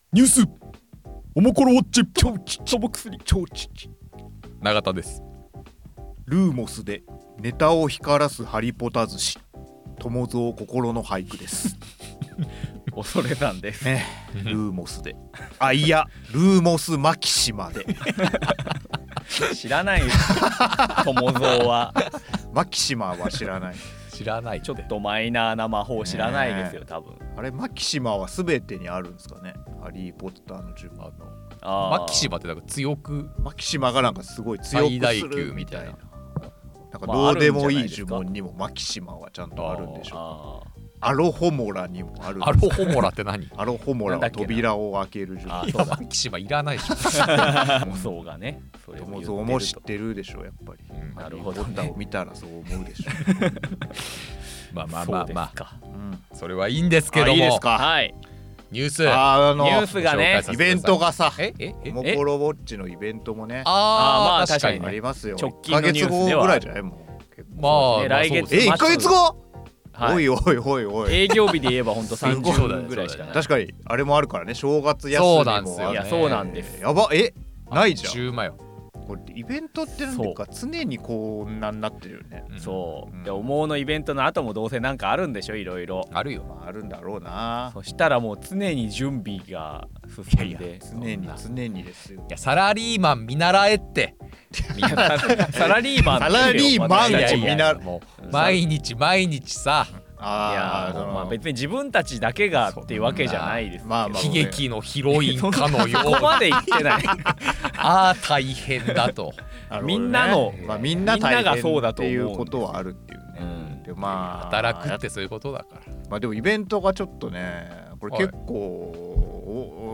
オモコロ編集部の3人が気になるニュースについて語ります。聞いても社会のことは何も知れません。